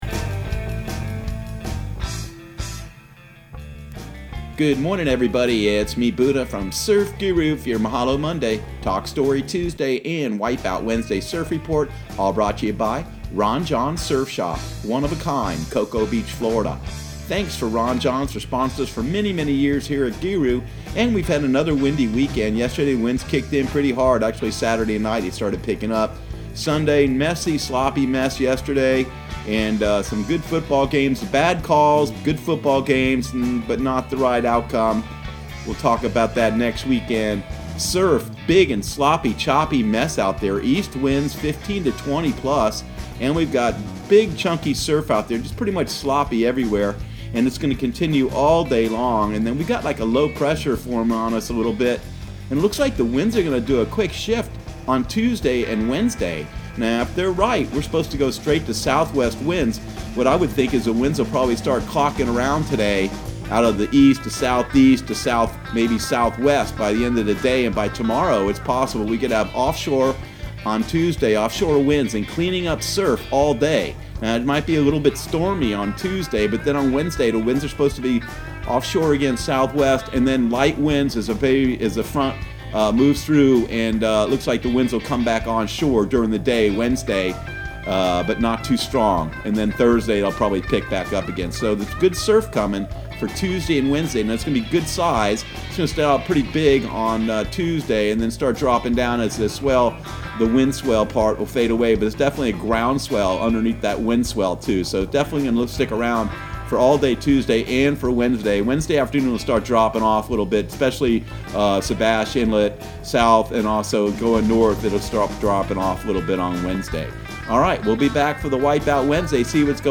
Surf Guru Surf Report and Forecast 10/07/2019 Audio surf report and surf forecast on October 07 for Central Florida and the Southeast.